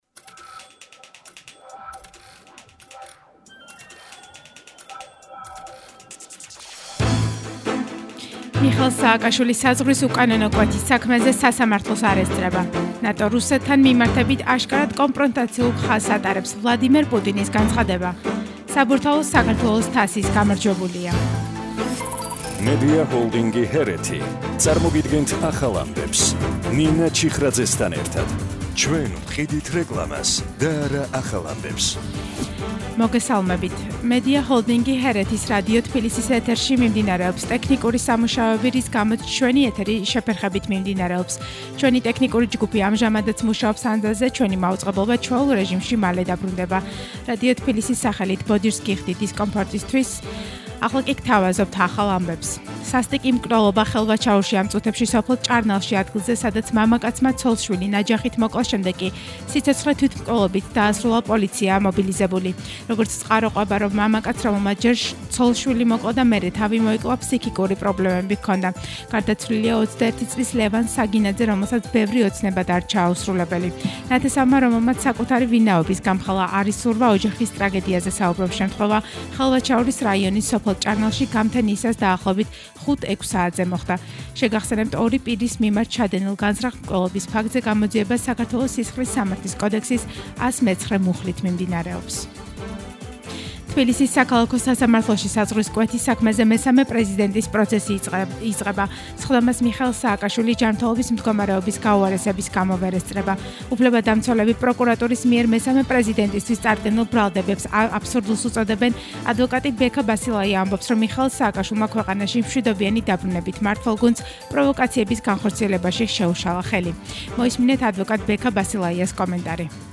ახალი ამბები 14:00 საათზე – 09/12/21 – HeretiFM